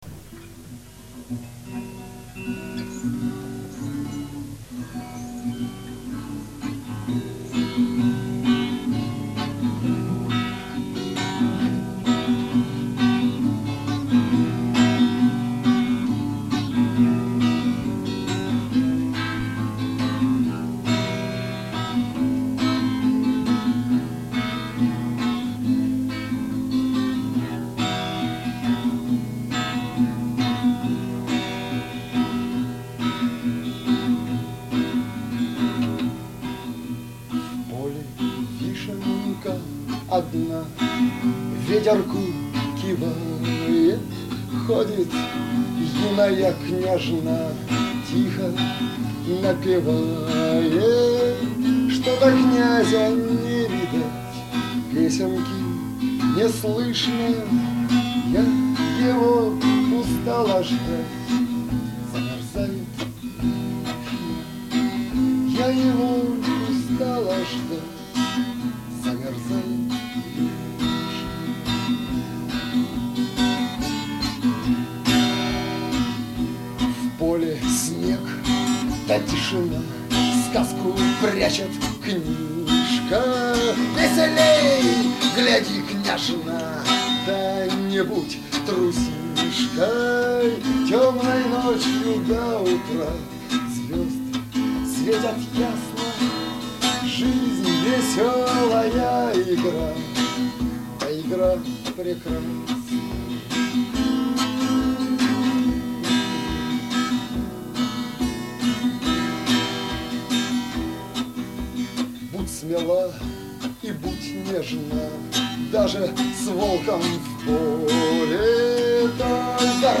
Русский рок Авторские песни